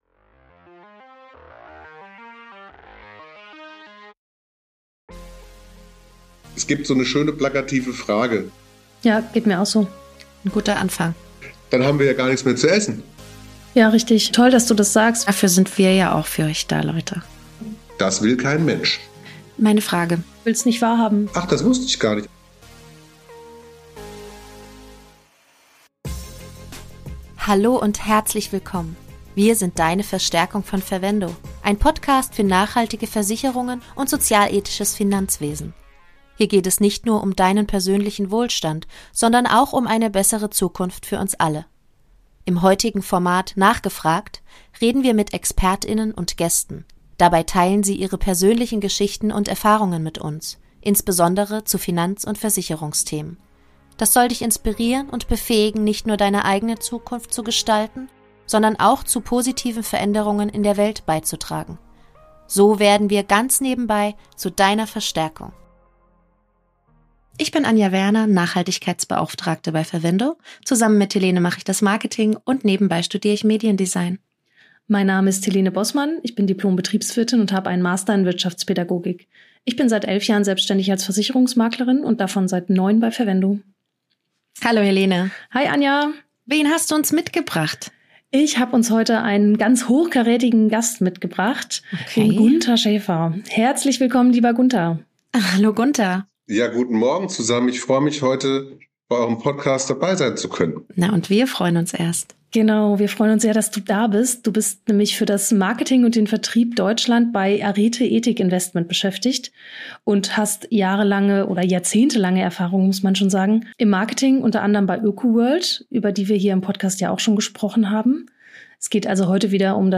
Ein tiefgründiges, ehrliches und motivierendes Gespräch über Geld, Verantwortung und die Zukunft.